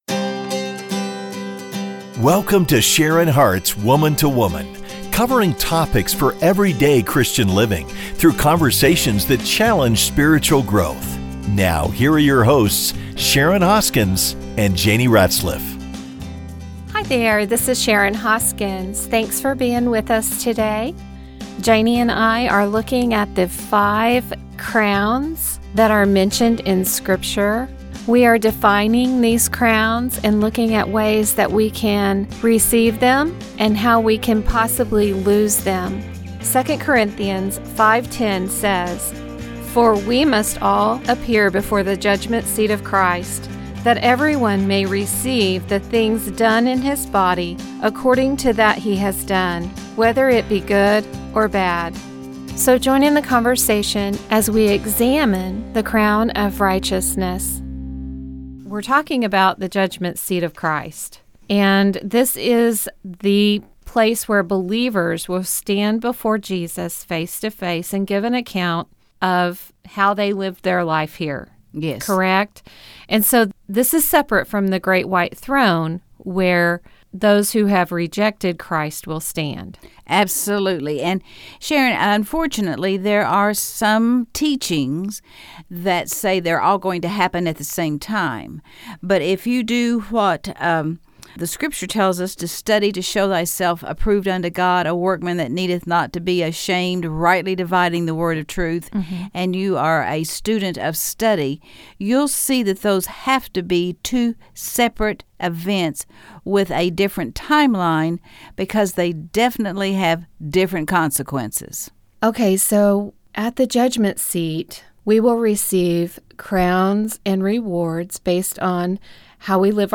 So, join in the conversation as we examine the Crown of Righteousness